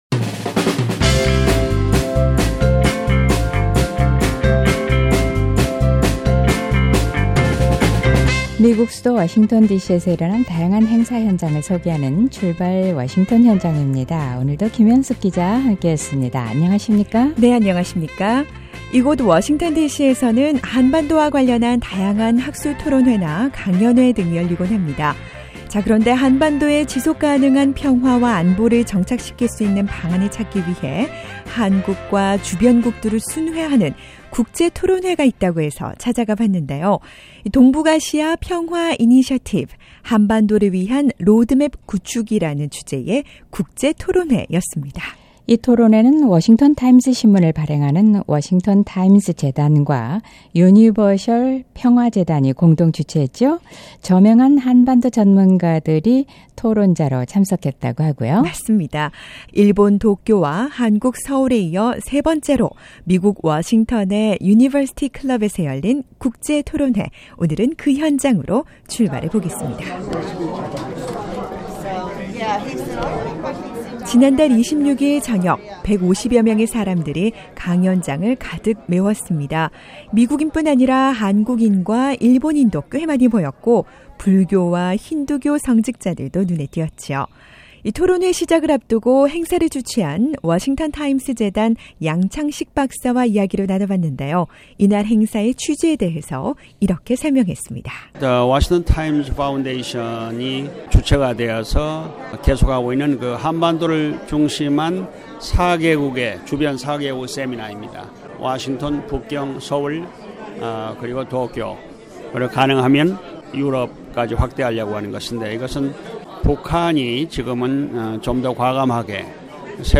워싱턴 토론회에서는 저명한 한반도 전문가들과 다양한 배경의 참석자들이 모여 한반도 평화를 위해 머리를 맞댔습니다. 워싱턴타임스재단이 주최한 ‘한반도를 위한 로드맵 구축’ 국제토론회 현장으로 출발해 봅니다.